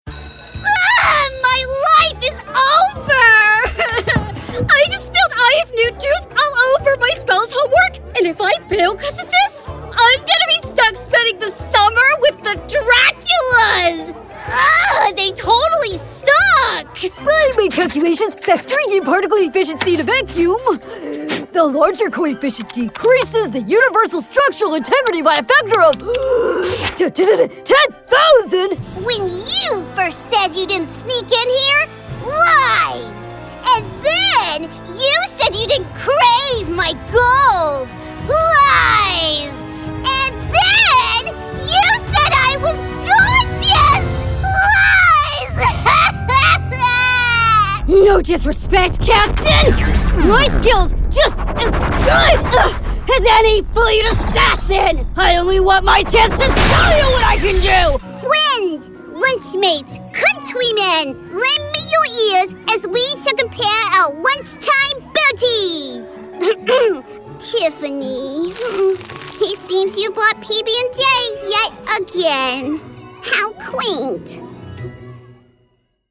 Animation
My voice has been described as sassy and quirky, but I can still be warm and comforting.